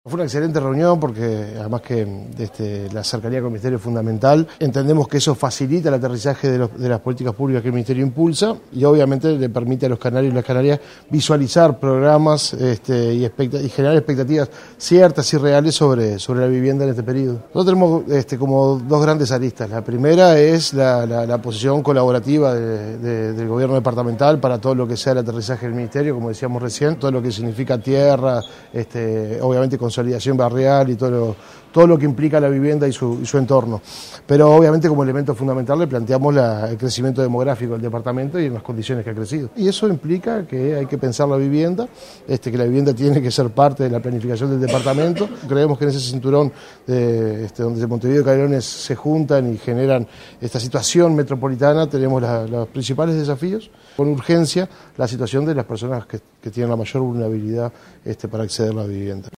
En tanto, el secretario general de la intendencia, Pedro Irigoin celebró lo positivo de la reunión y destacó las condiciones en las que ha crecido la población del departamento, con énfasis en quienes tiene mayor dificultad de acceso a la vivienda.